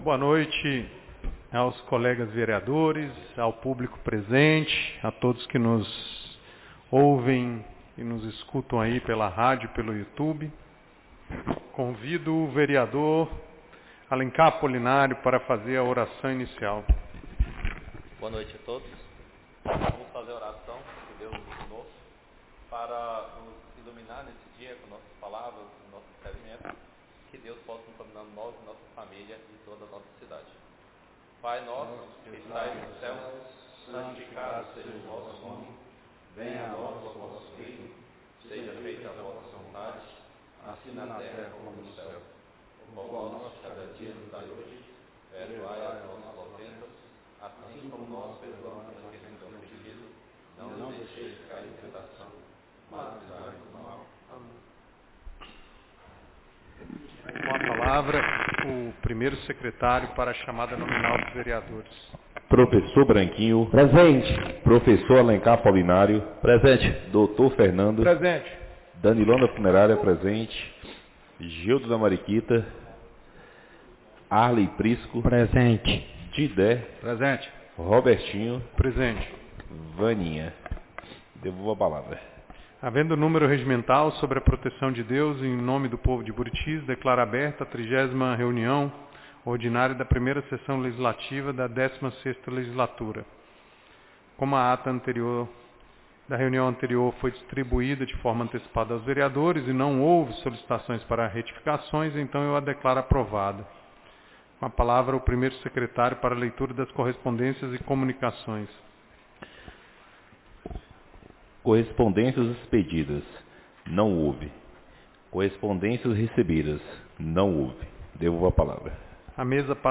30ª Reunião Ordinária da 1ª Sessão Legislativa da 16ª Legislatura - 09-09-25